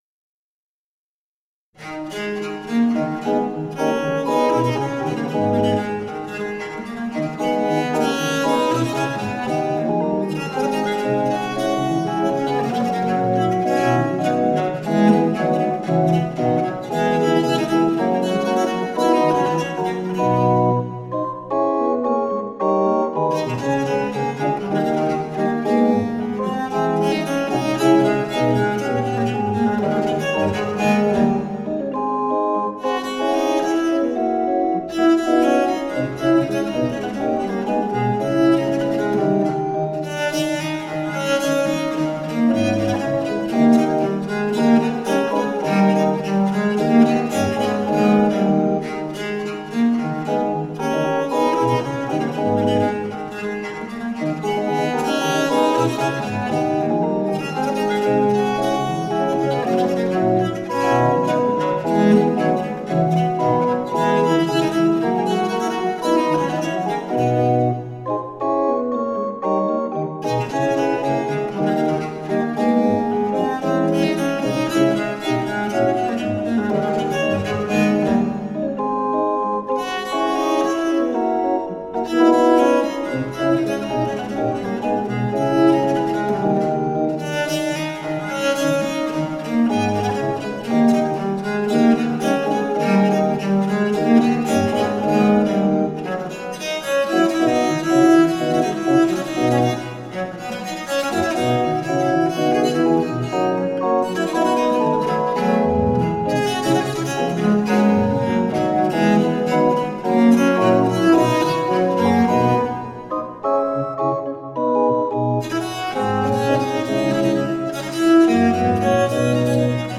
German Sonatas for Viola da Gamba from the Baroque period.